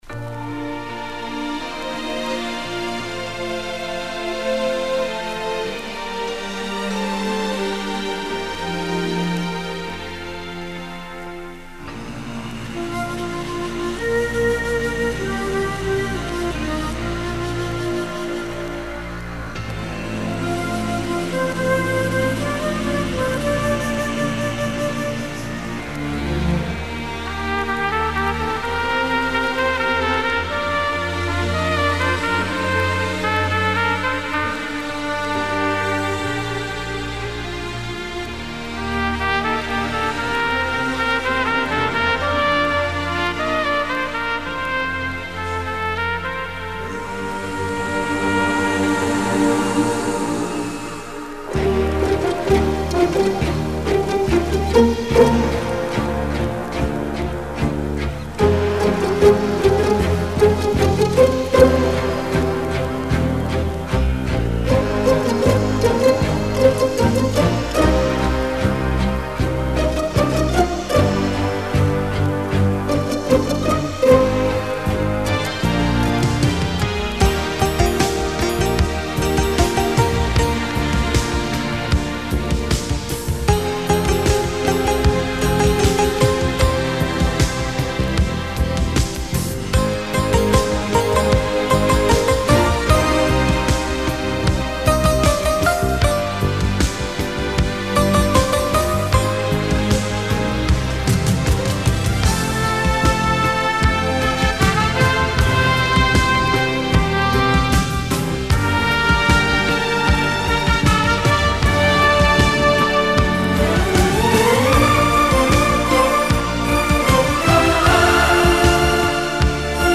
VERSIONS INSTRUMENTALES